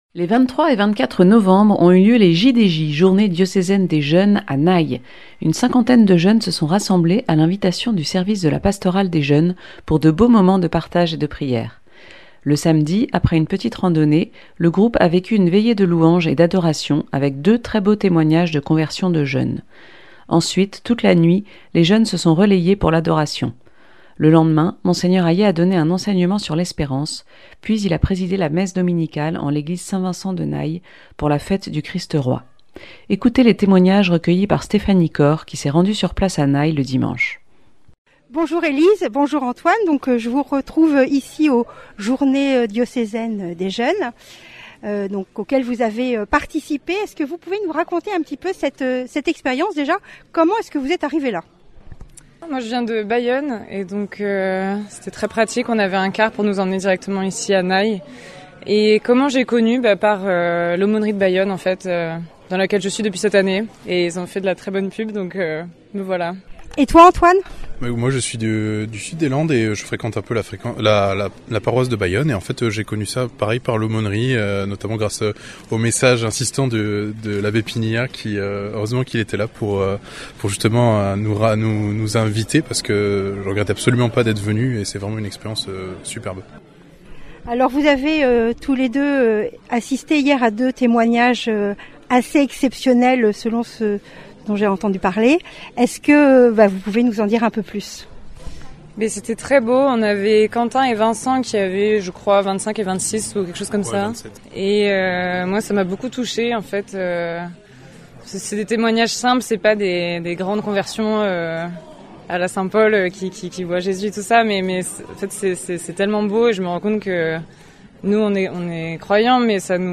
Reportage sur la Journée Diocésaine des Jeunes.